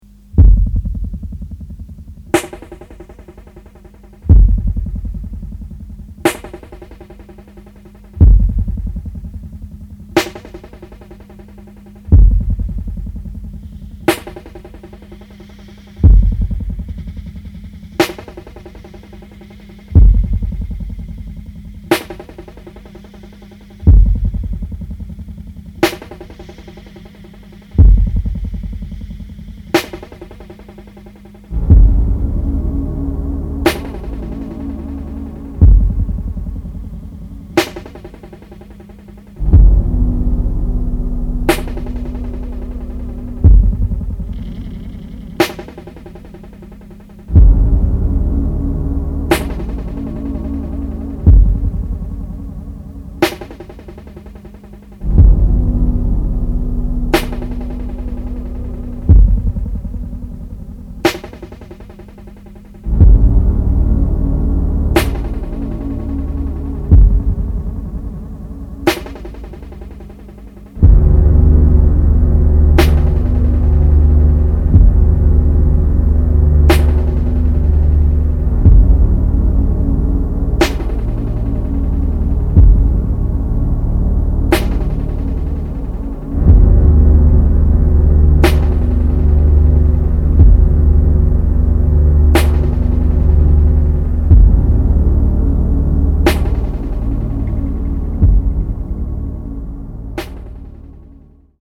ミニマル　サウンドスケープ　宅録